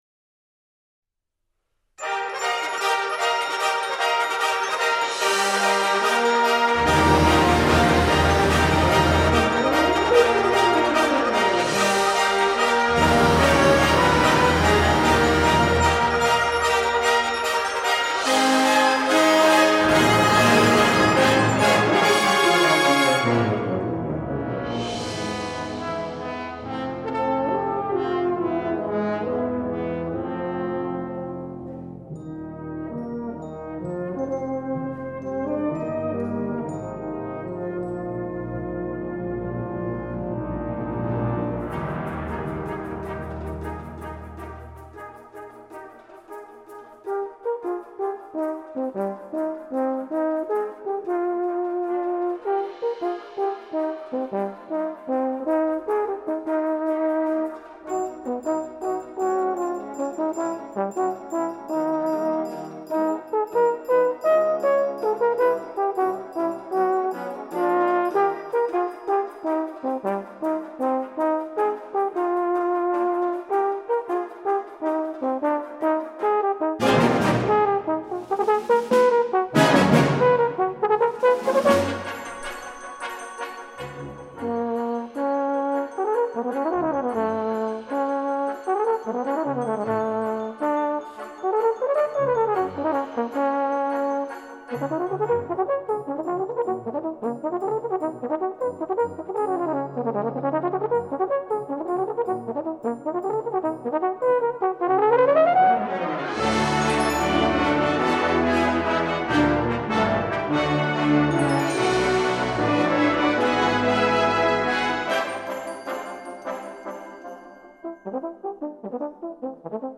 Brass Band
Solo & Brass Band